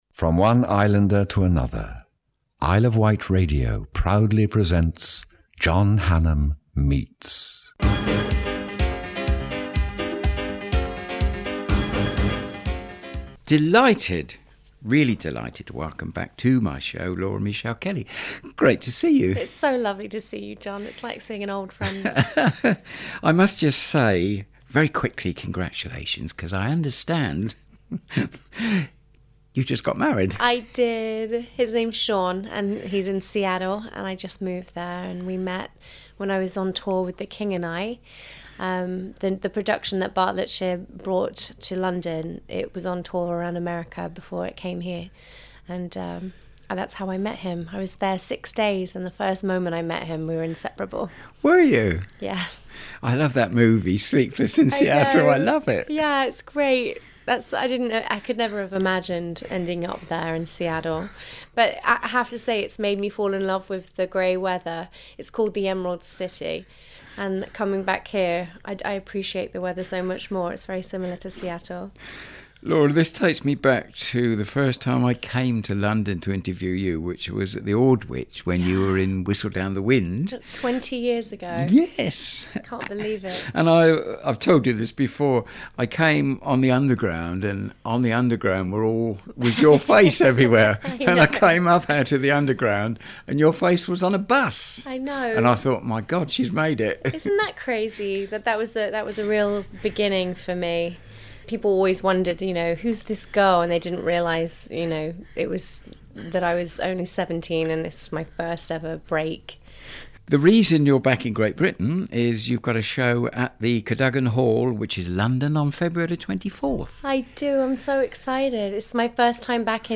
interviews celebrities on their life and work